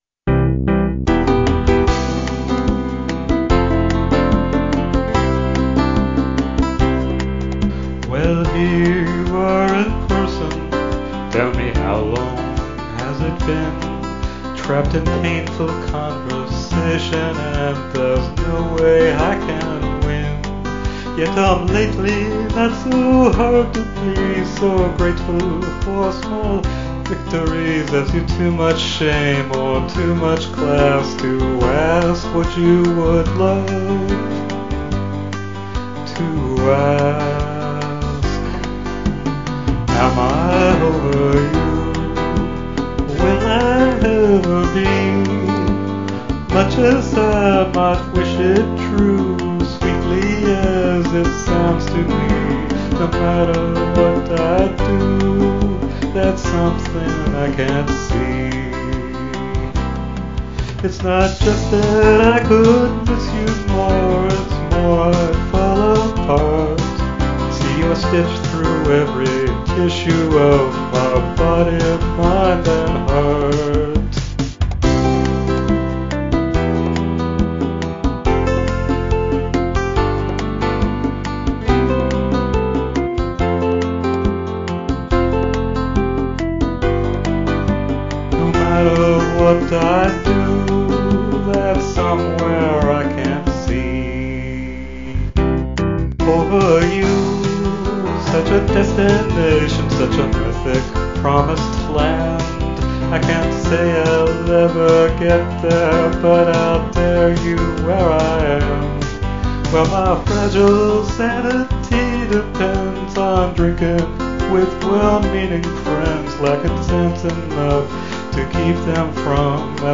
medium folk 4/4 female or male voice